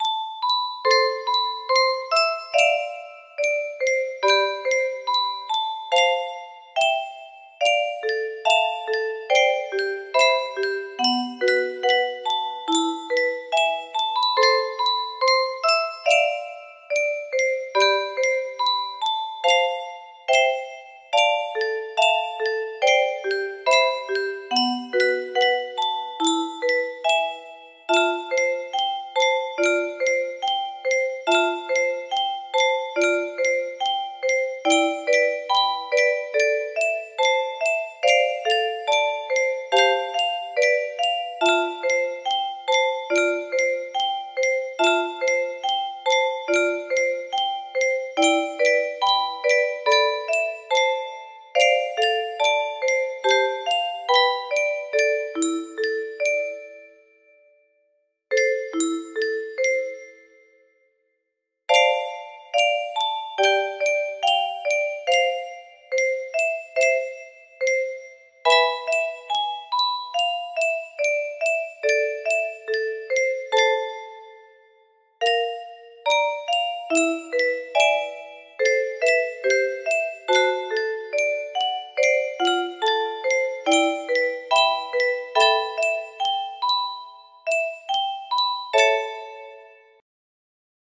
sd3 music box melody